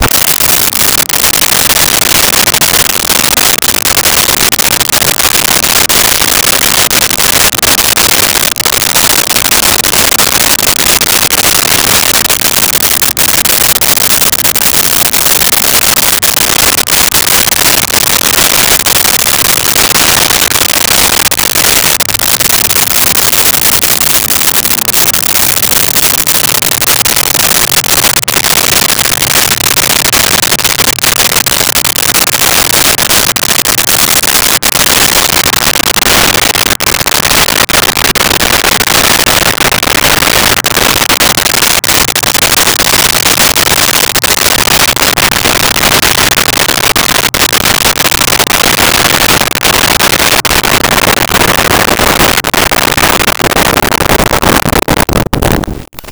Car Start Idle Away
Car Start Idle Away.wav